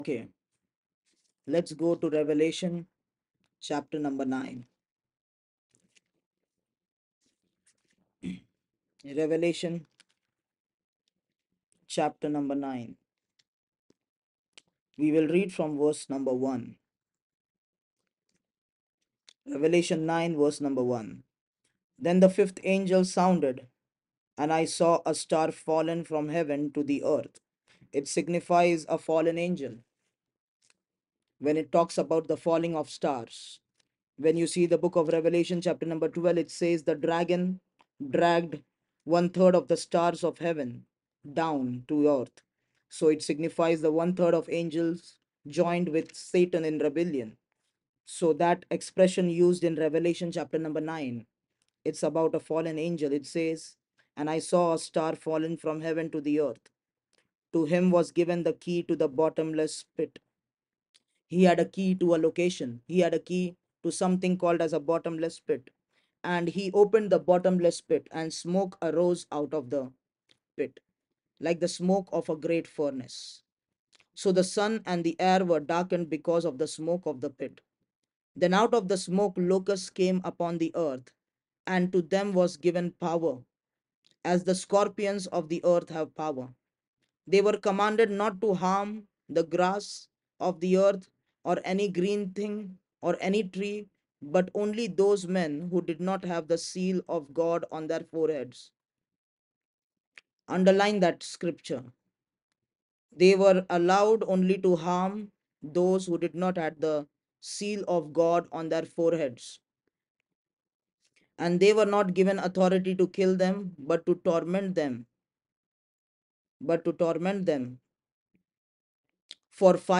audio sermons - Abaddon, the angel of the bottomless pit